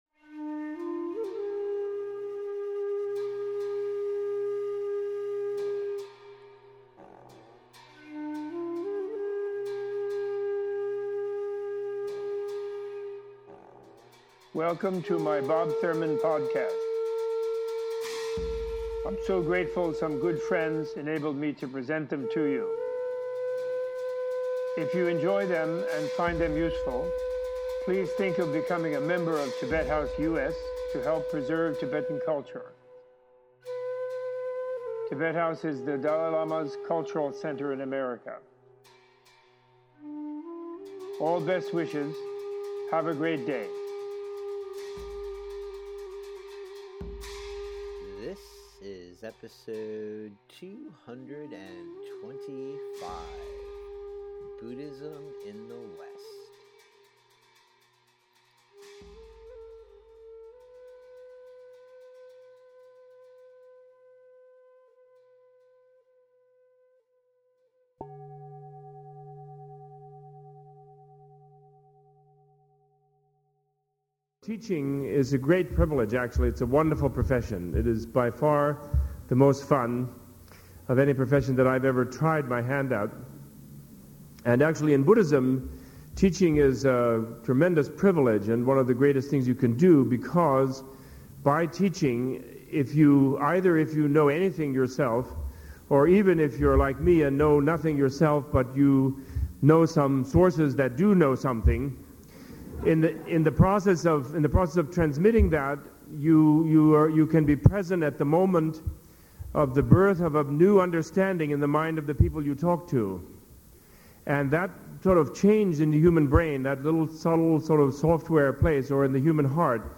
Examining the spread of Buddhism in the West through the organizations and communities found in the United States Robert A.F. Thurman gives a teaching on the Buddhist methods any individual can use in their pursuit of happiness no matter their background.